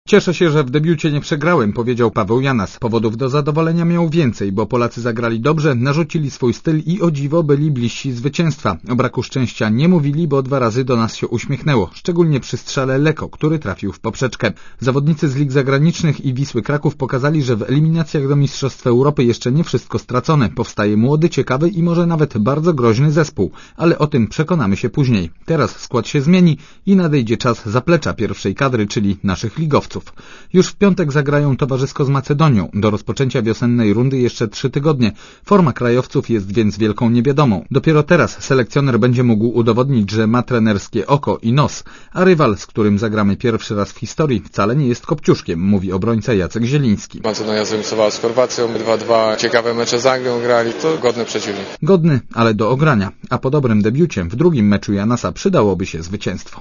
Komentarz audio (225Kb)